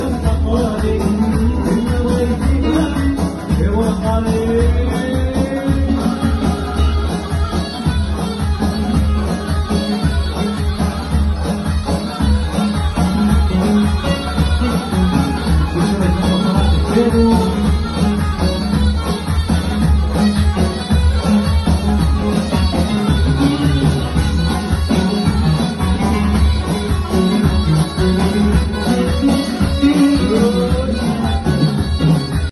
performing live on stage
controlling 3 keyboards at once